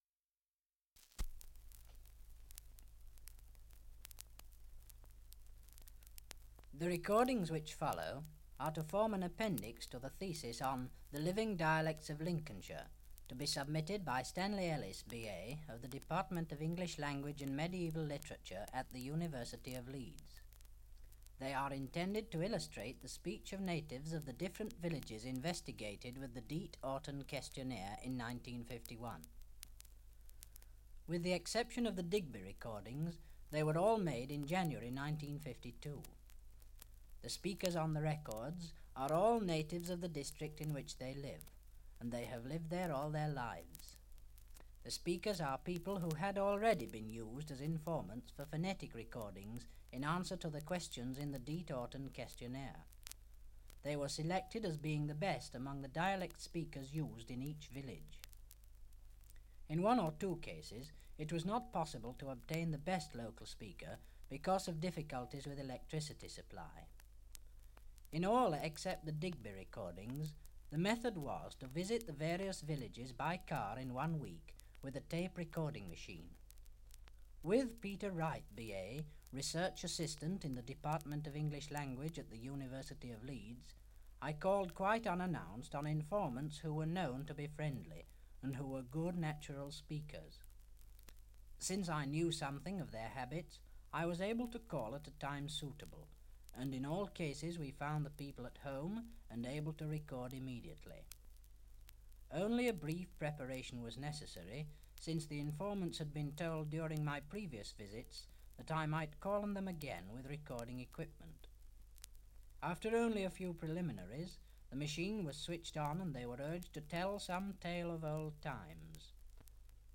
Introduction to Lincolnshire Dialect. Survey of English Dialects recording in Eastoft, Lincolnshire
78 r.p.m., cellulose nitrate on aluminium